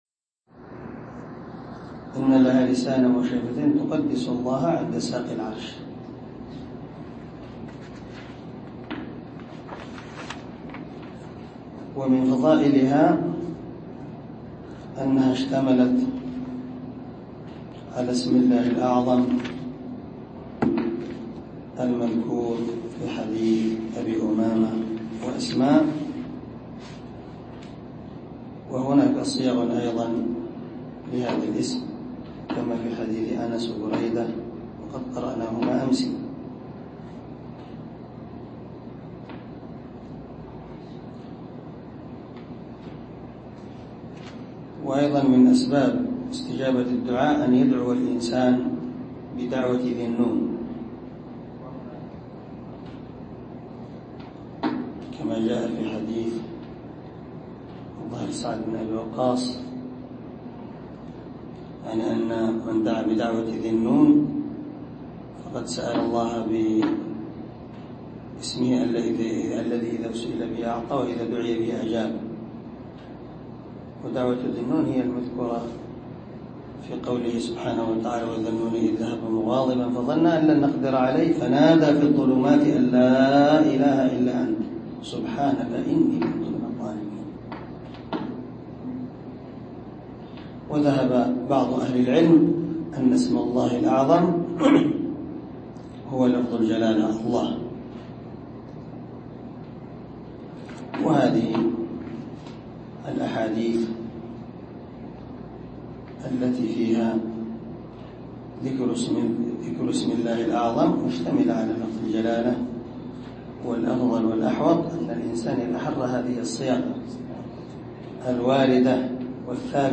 عنوان الدرس: الدرس الخامس عشر
دار الحديث- المَحاوِلة- الصبيحة.